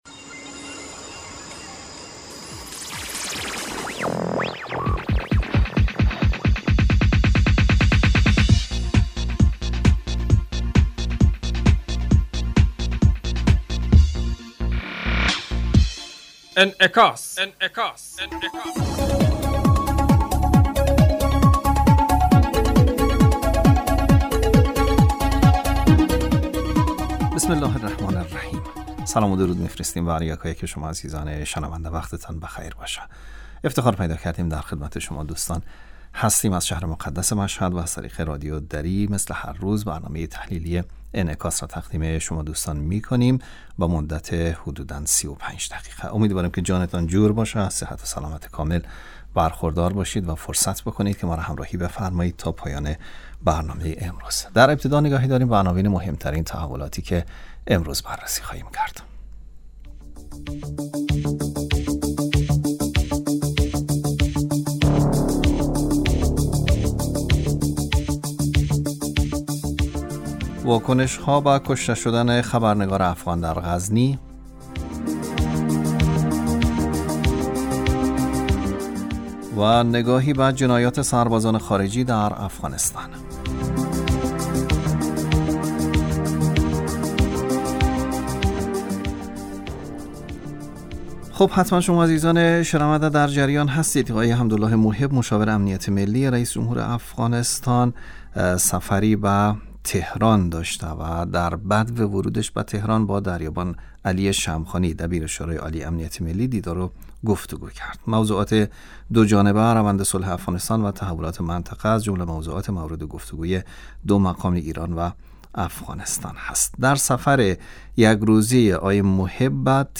برنامه انعکاس به مدت 35 دقیقه هر روز در ساعت 12:00 ظهر (به وقت افغانستان) بصورت زنده پخش می شود.